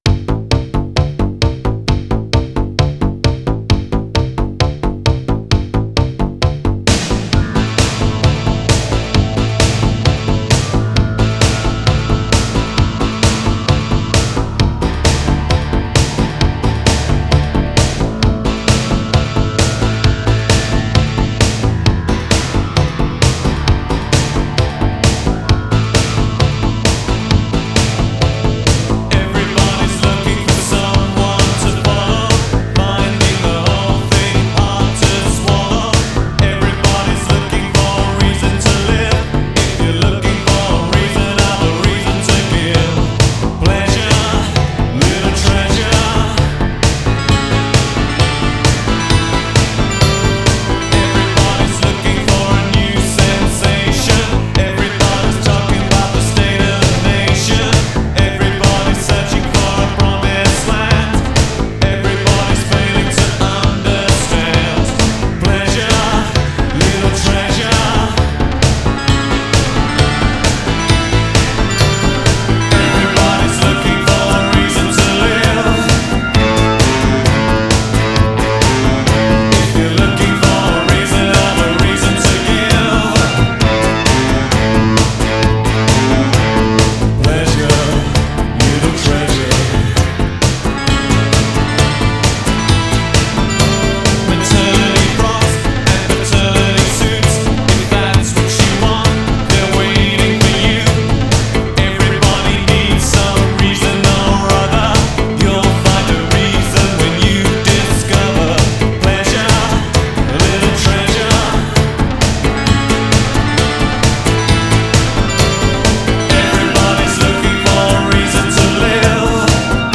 1987 Synth pop